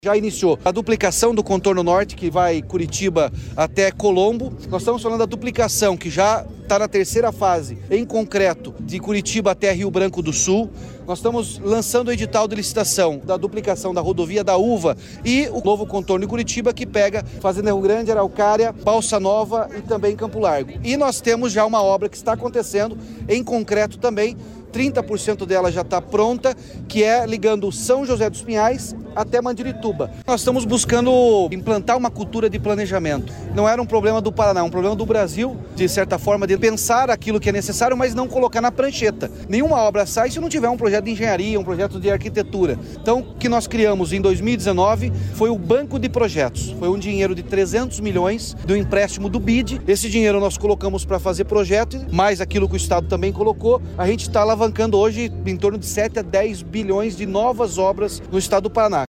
Sonora do governador Ratinho Junior sobre o pacote de obras na Região Metropolitana de Curitiba